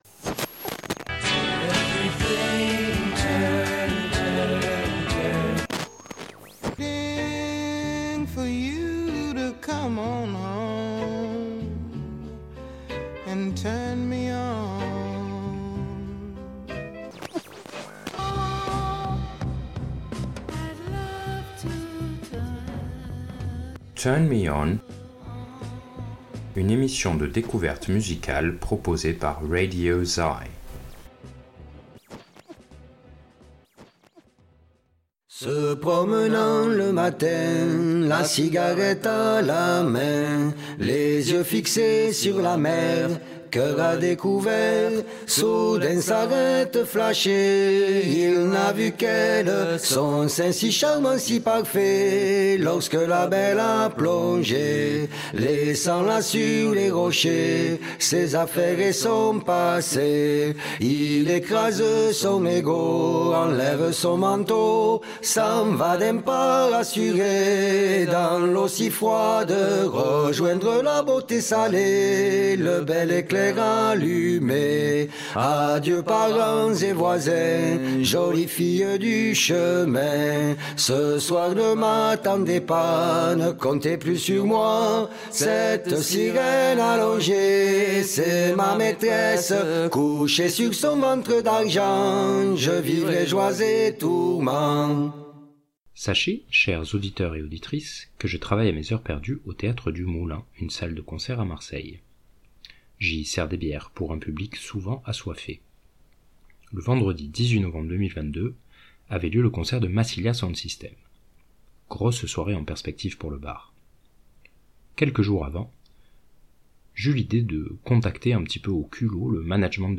Une interview très instructive et chill out